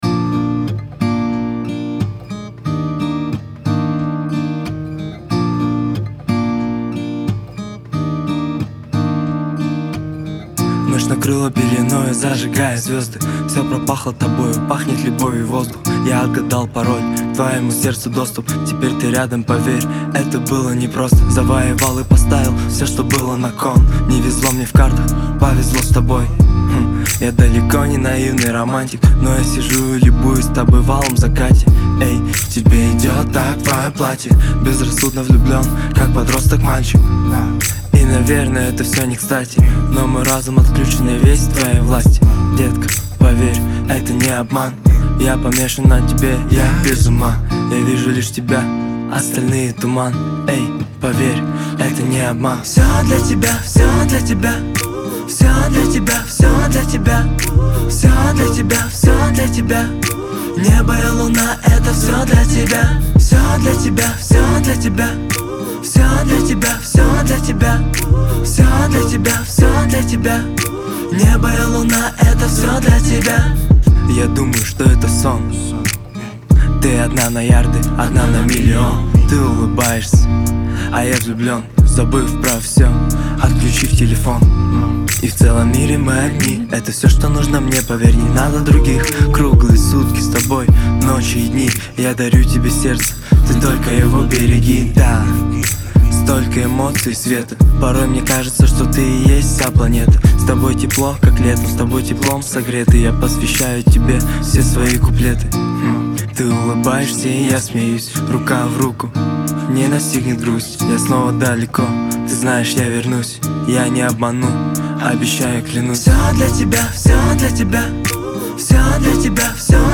это трек в жанре поп-рок